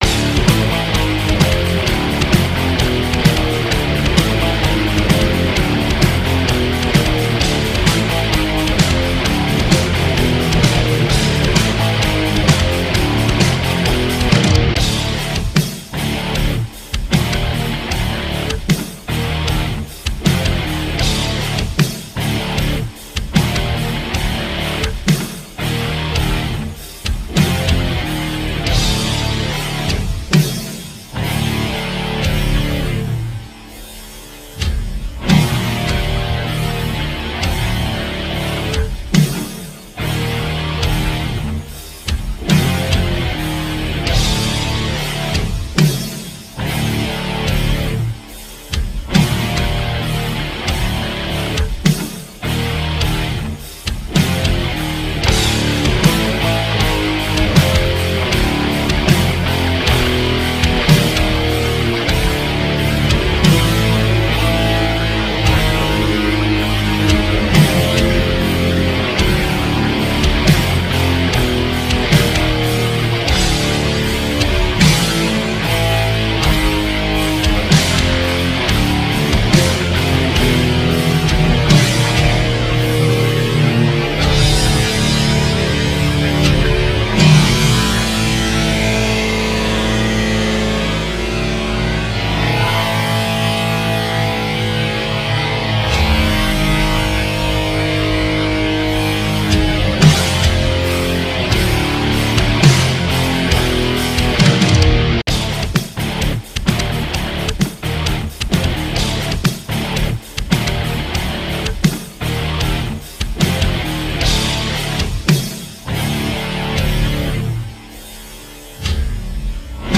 Here is a clip of me messing around with the Harmor software synthesizer.
Filed under: Synthesizers